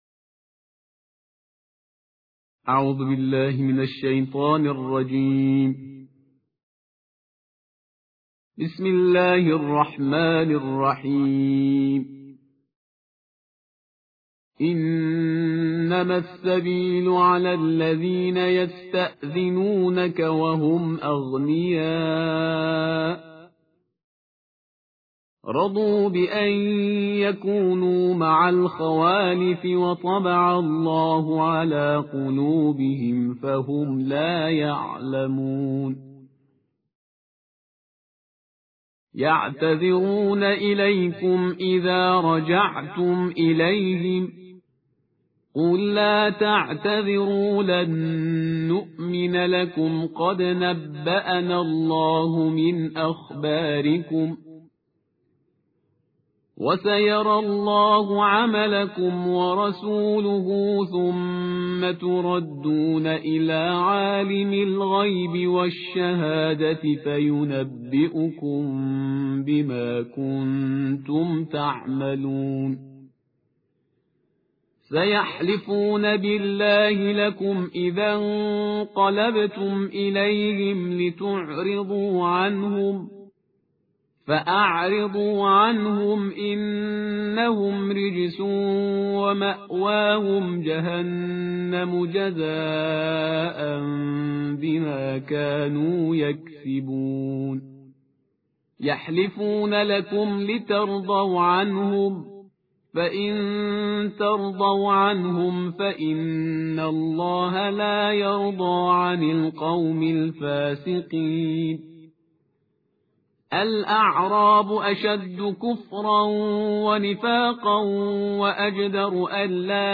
ترتیل جزء یازدهم قرآن کریم+صوت